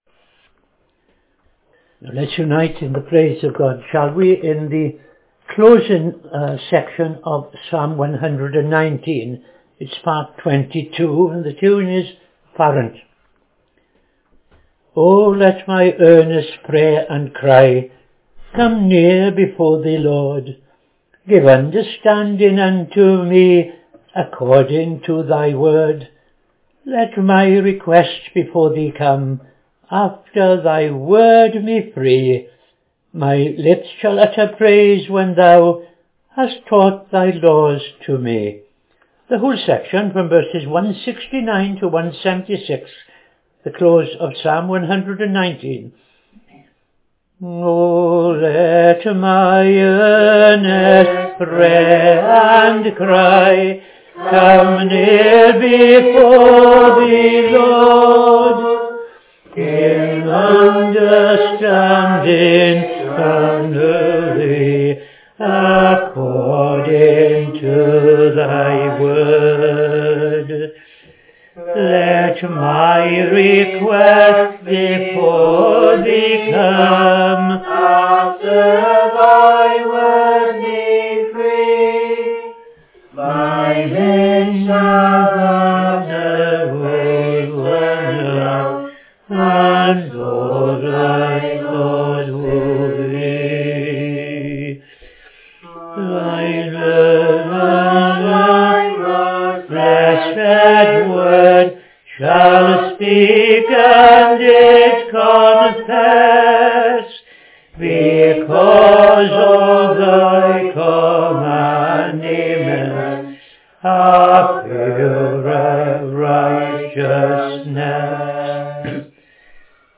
Evening Service - TFCChurch
5.00 pm Evening Service Opening Prayer and O.T. Reading I Chronicles 28:1-21